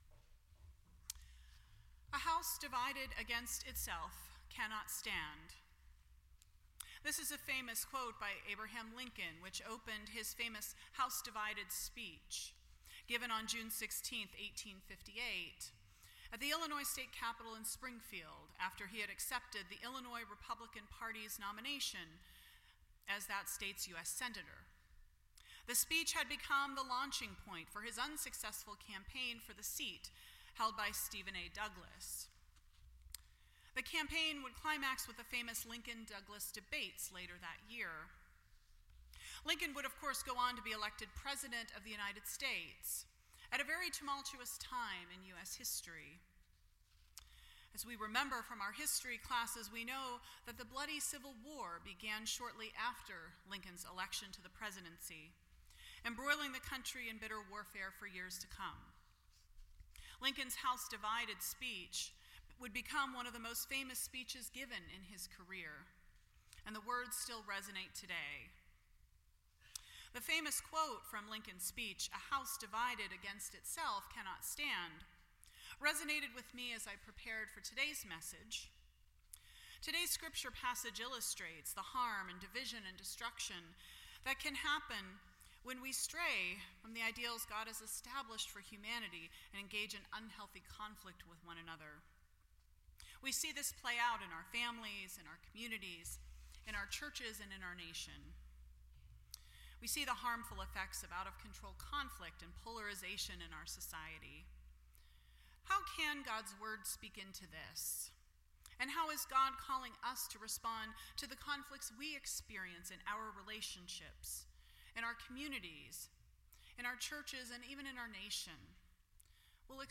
The Story Service Type: Sunday Morning %todo_render% Share This Story